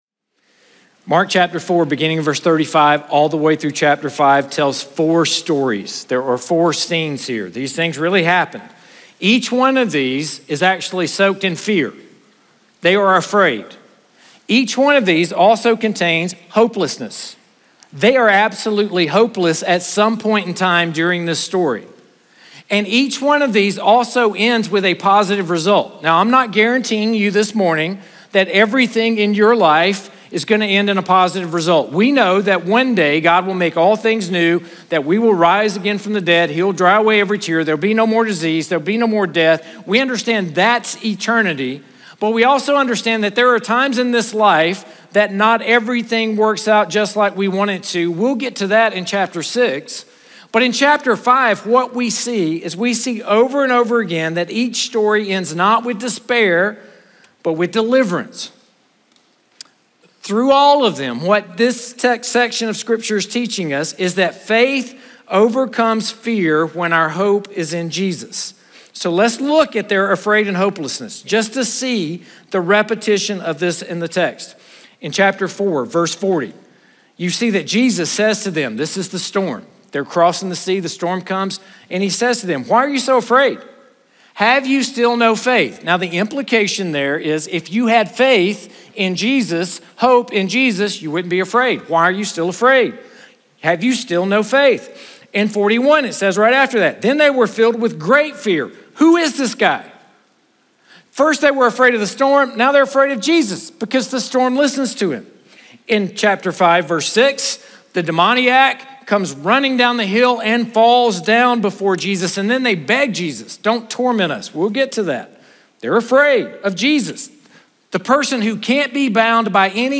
Chapel Messages